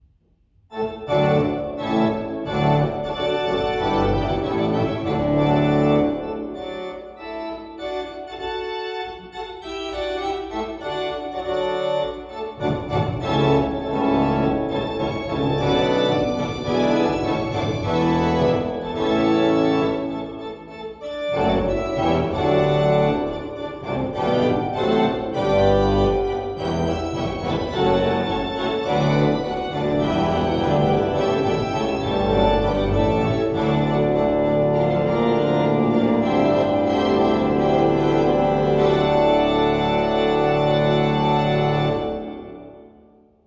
Monarke orgels zijn voorzien van een groot audio systeem, wat zorgt voor een mooie transparante klank, zelfs tot in het volle werk.
Beluister hier een geluidsfragment van het Monarke orgel in de Vaartkerk.
Monarke-Präeludium Eemdijk 2 man | ped | 35 stemmen | Orgelstijl: Barok